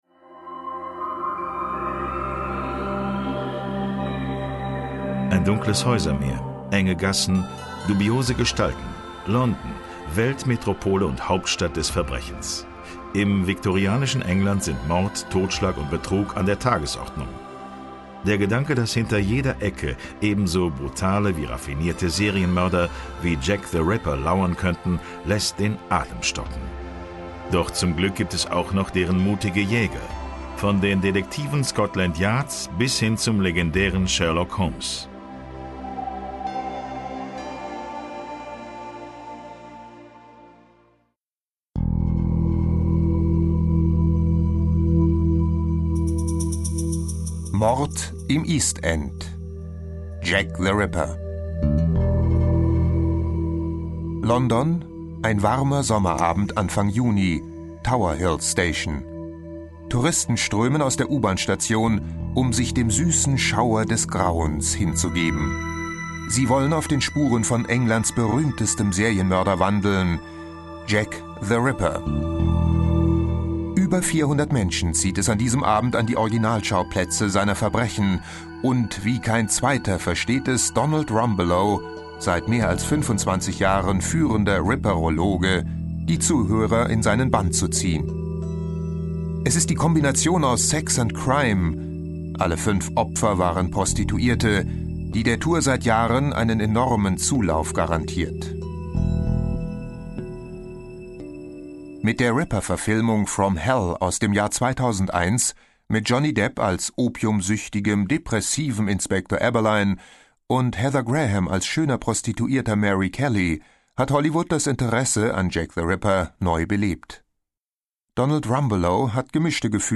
Schlagworte Audio-CD • Audio-CD, Kassette / Sachbücher/Geschichte • AUDIO/Sachbücher/Geschichte • England • England, Geschichte; Geistes-/Kultur-Geschichte • England, Geschichte; Geistes-/Kultur-Geschichte (Audio-CDs) • Geschichte • Geschichtsdokumentation • Geschichtsdokumentationen (Audio-CDs) • Hörbücher • London • London, Geschichte; Geistes-/Kultur-Geschichte • London, Geschichte; Geistes-/Kultur-Geschichte (Audio-CDs) • Sherlock Holmes • Viktorianisches Zeitalter; Geistes-/Kultur-G. • Viktorianisches Zeitalter; Geistes-/Kultur-G. (Audio-CDs)